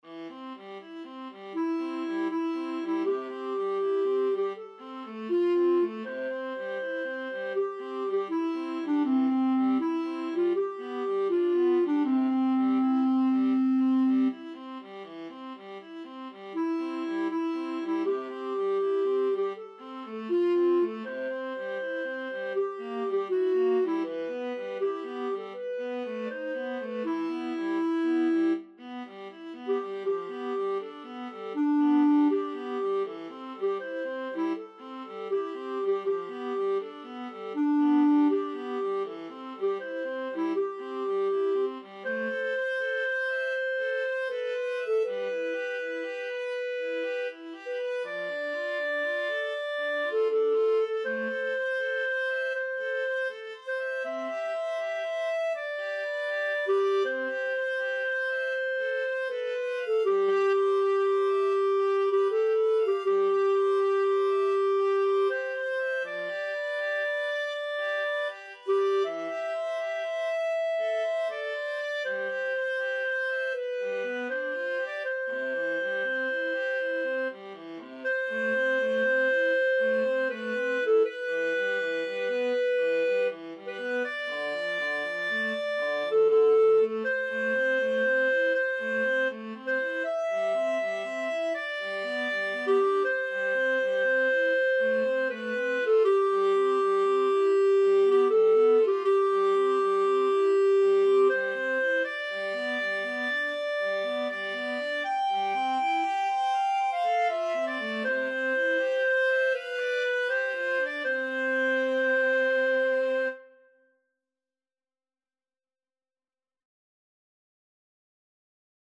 Free Sheet music for Clarinet-Viola Duet
C major (Sounding Pitch) (View more C major Music for Clarinet-Viola Duet )
Andante
Traditional (View more Traditional Clarinet-Viola Duet Music)